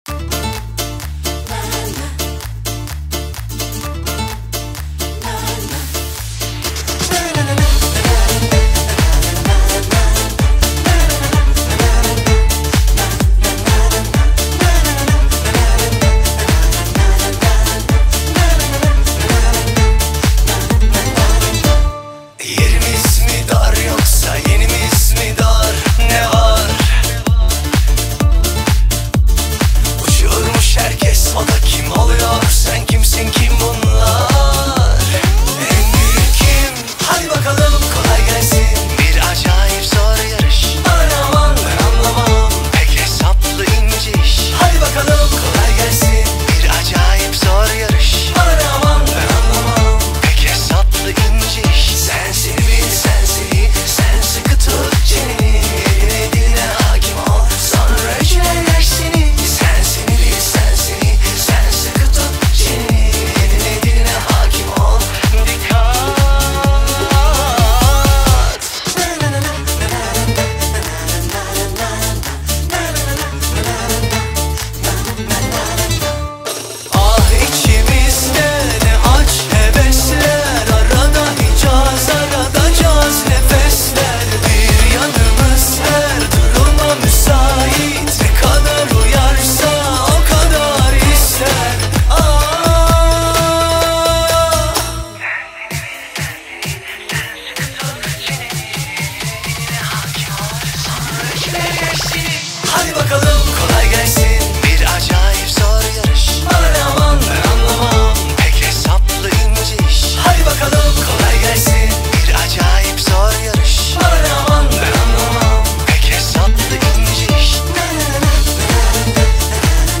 BPM64-128
Audio QualityPerfect (High Quality)
This is a Turkish song from my childhood.